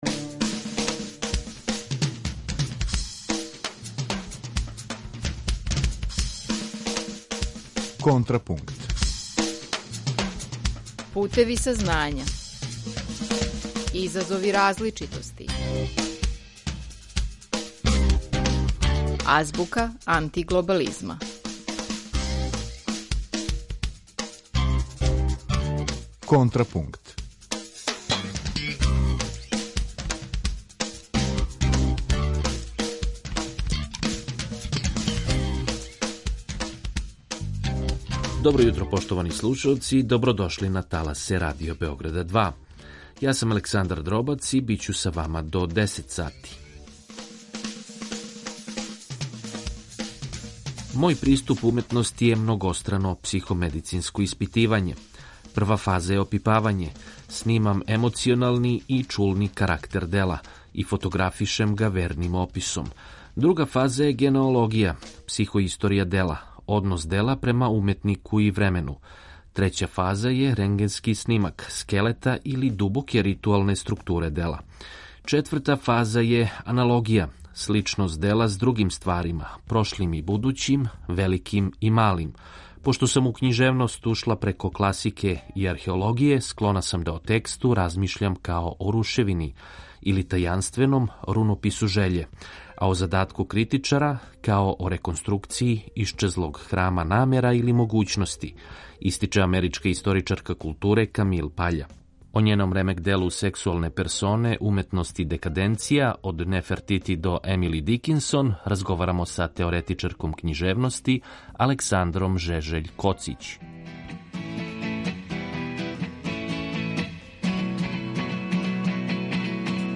Радио Београд 2